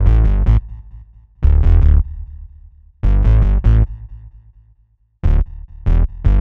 Bass 39.wav